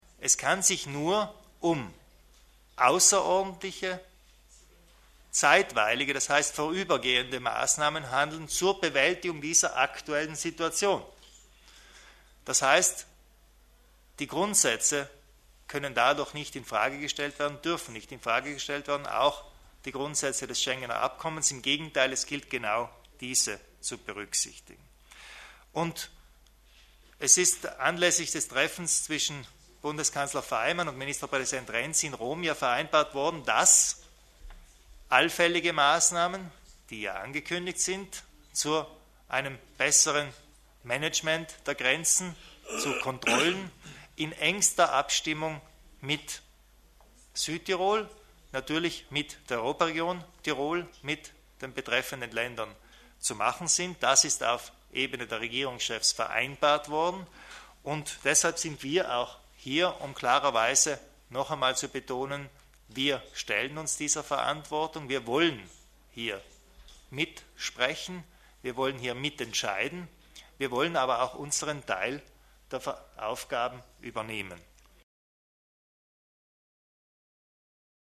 Landeshauptmann Kompatscher zur Lösung des Flüchtlingsproblems am Brenner